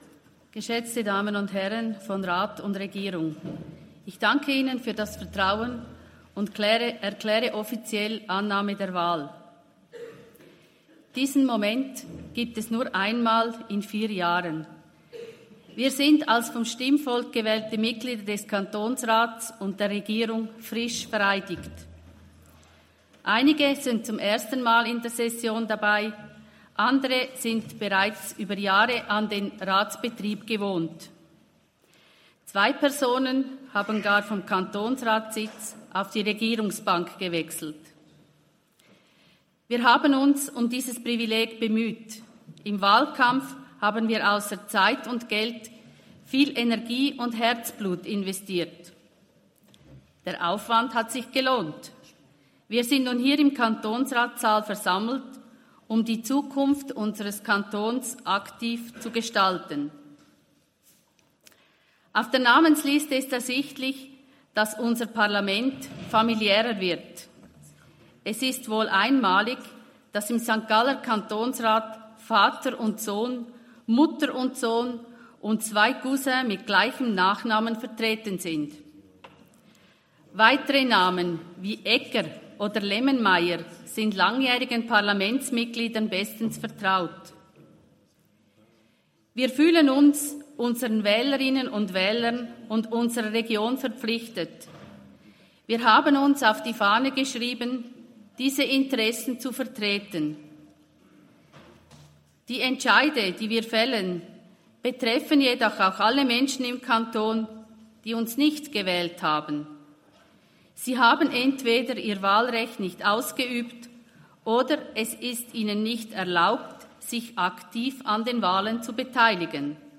Session des Kantonsrates vom 3. und 4. Juni 2024, Sommersession